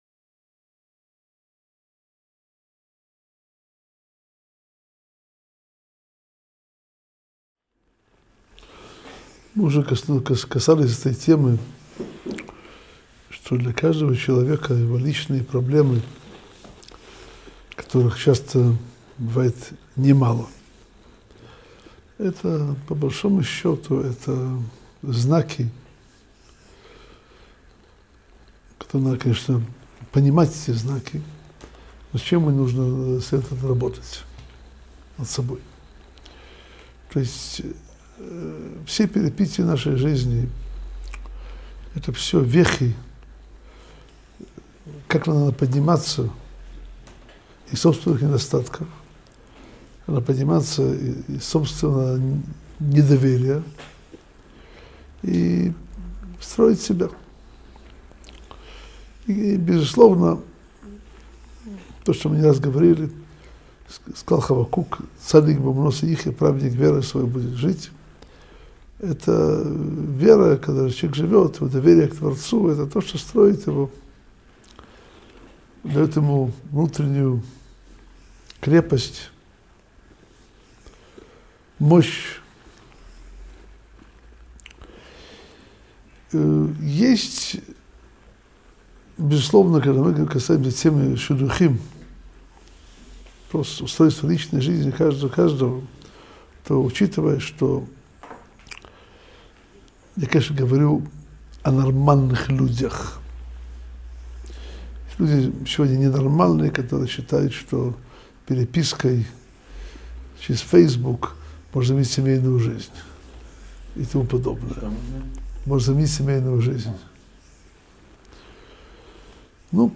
Урок № 13.